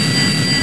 Index of /~p1/quake2/weapons/sound/jetpack
running.wav